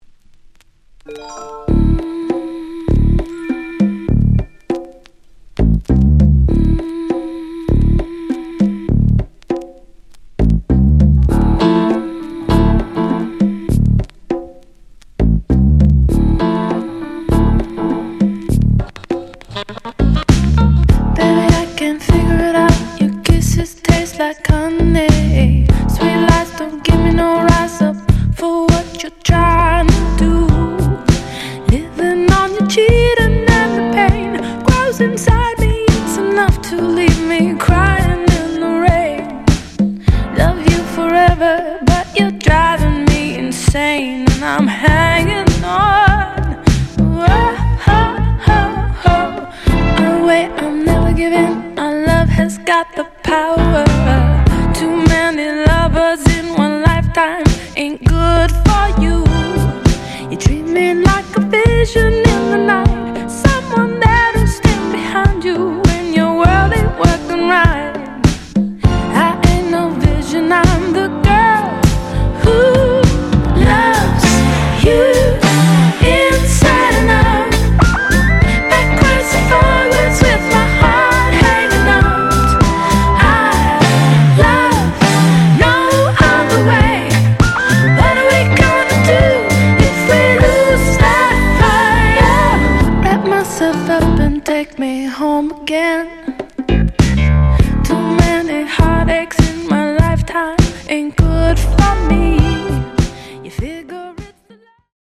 現在の耳にもしっくりくるダウンテンポ具合が◎！